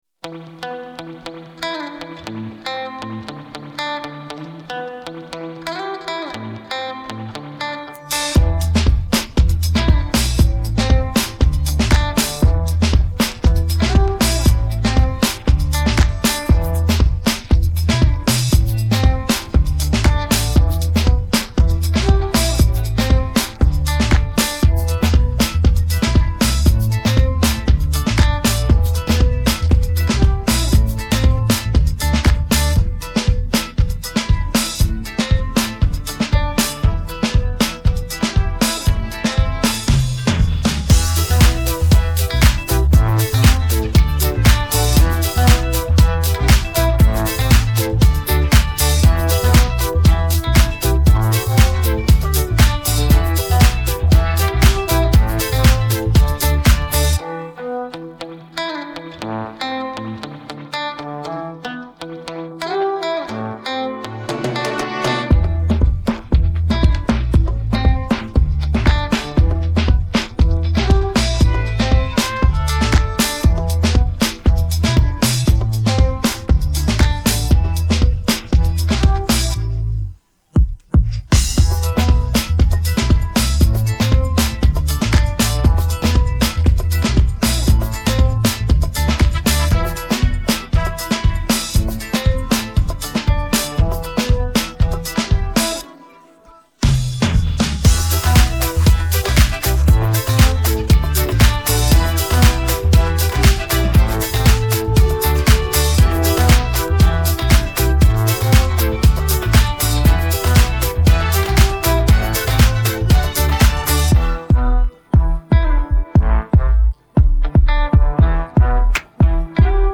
Música de fondo